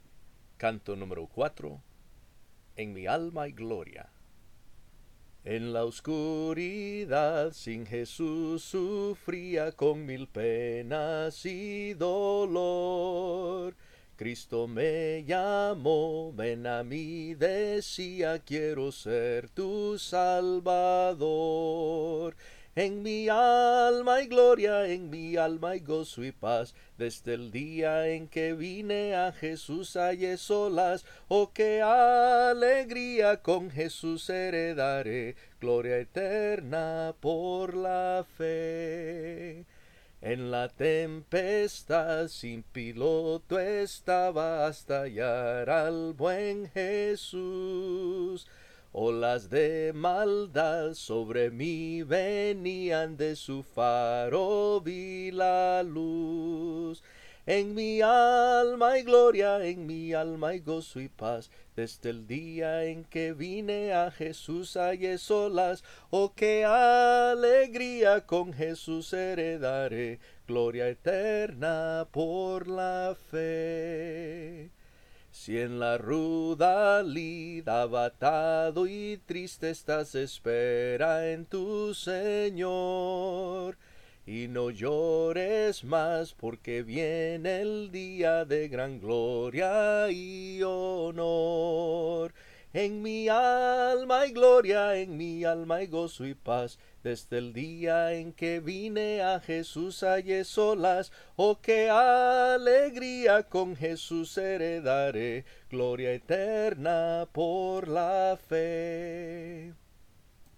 Se ha optado por interpretar únicamente la melodía, prescindiendo de las voces de armonía (alto, tenor y bajo) con el propósito de facilitar el proceso de aprendizaje. Al centrarse exclusivamente en el soprano (la melodía), el oyente puede captar con mayor claridad las notas y matices sonoros.